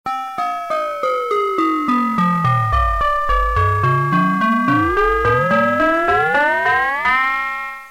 3. dass bei chromatisch nach oben oder unten verlaufenden Tonhöhen von Ue 1 und Ue 2 sich die Seitenbänder quasi parallel zu Ue 1 und Ue 2 weiterbewegen (Mixturklang).
Die Klangbeispiele sind mit einem Sinusoszillator (Ue 1) und einem E-Piano (Ue 2) hergestellt.